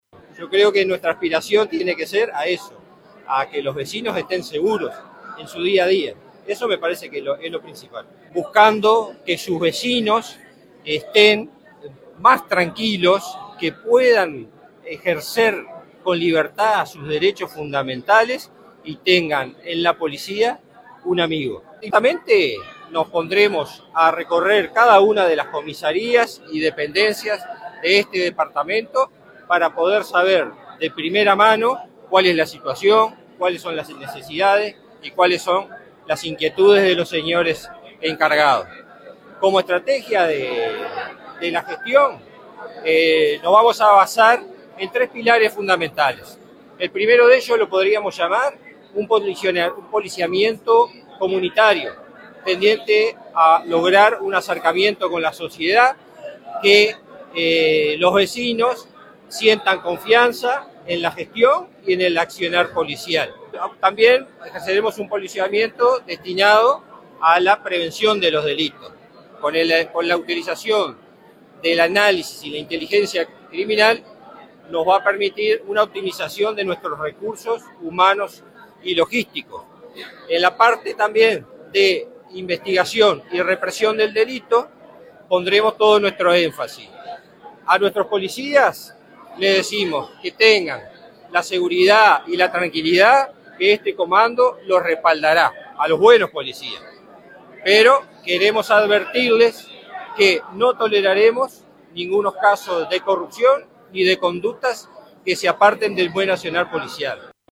El comisario general retirado Osvaldo Molinari asumió este lunes como nuevo jefe de Policía de San José en una ceremonia realizada en la Escuela Departamental de Policía, que contó con la presencia del ministro del Interior, Carlos Negro, y autoridades nacionales y departamentales.
OSVALDO-MOLINARI-JEFE-DE-POLICIA-DE-SAN-JOSE.mp3